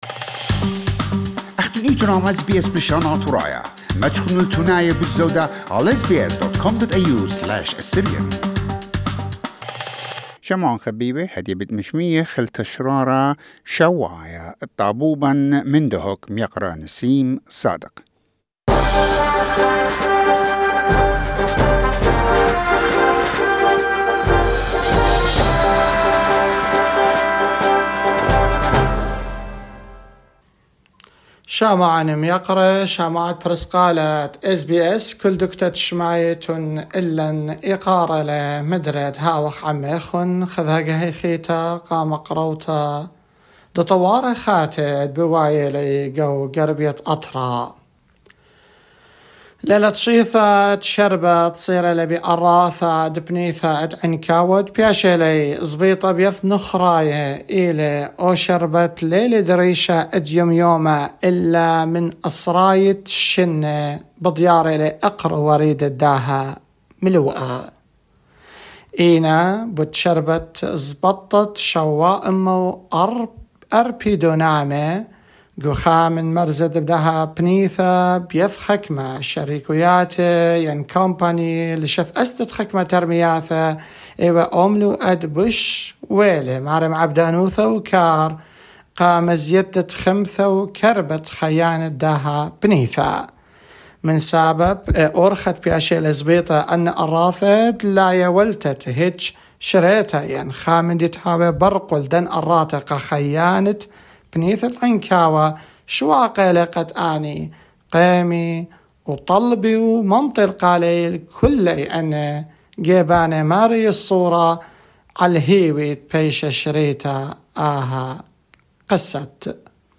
A weekly report providing an update on the current affairs relating to our community in northern Iraq.